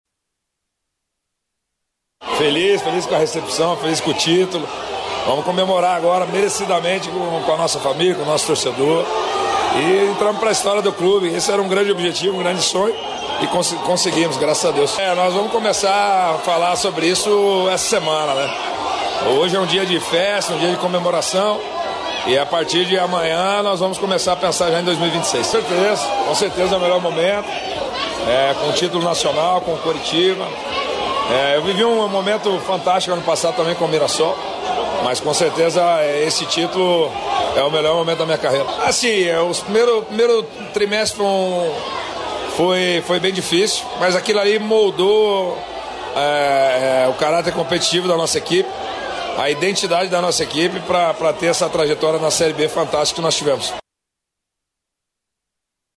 Logo após a chegada da equipe, a festa aumentou.